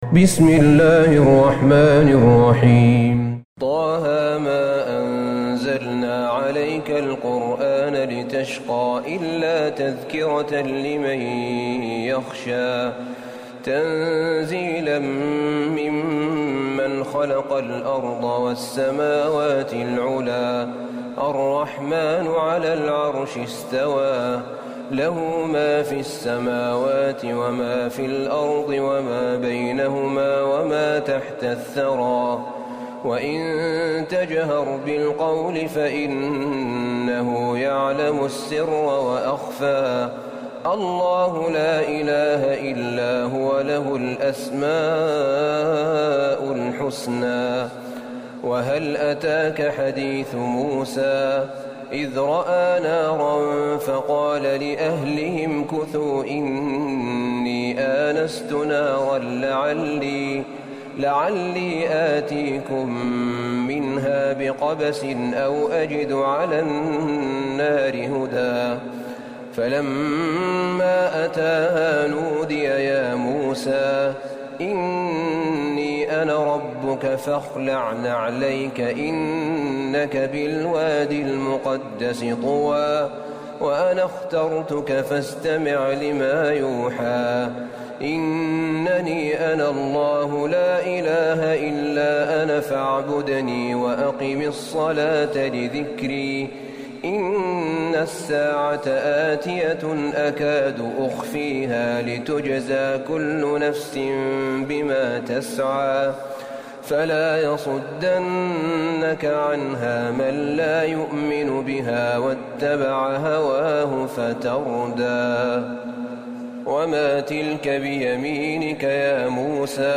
سورة طه Surat TaHa > مصحف الشيخ أحمد بن طالب بن حميد من الحرم النبوي > المصحف - تلاوات الحرمين